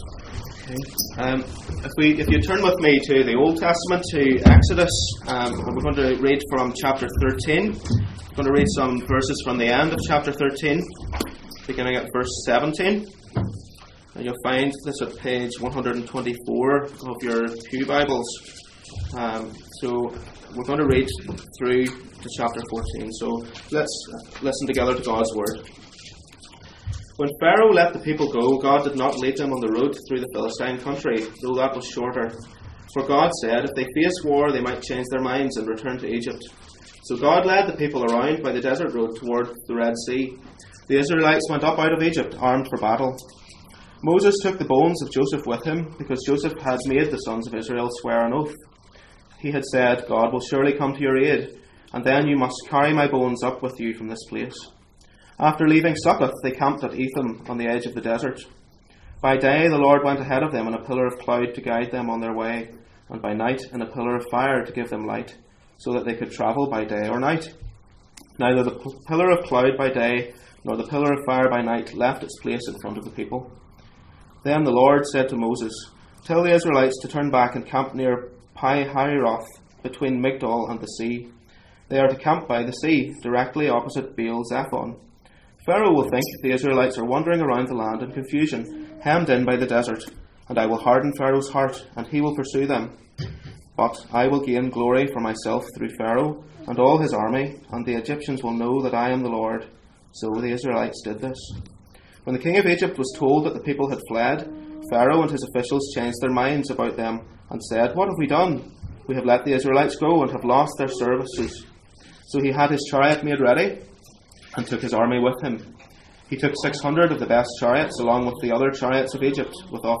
1 Corinthians 10:1-13 Service Type: Sunday Morning %todo_render% « Ephesus